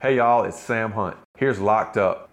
LINER Sam Hunt (Locked Up) 1